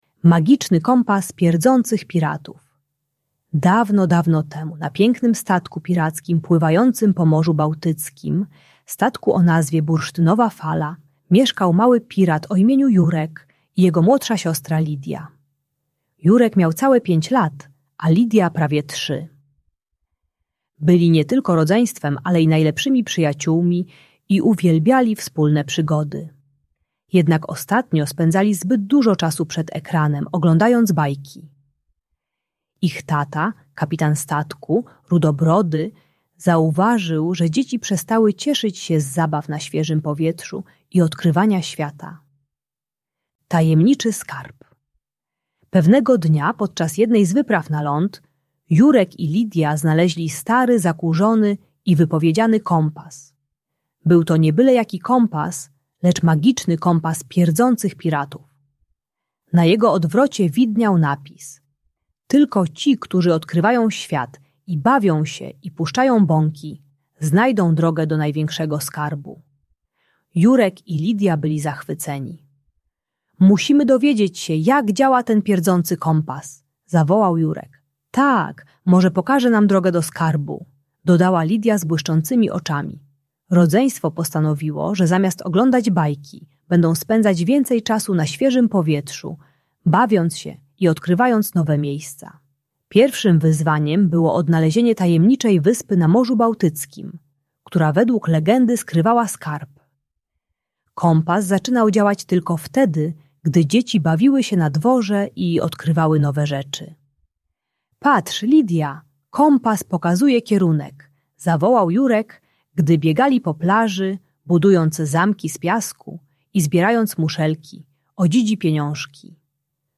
Magiczny Kompas Pirackiej Przygody - Bajki Elektronika | Audiobajka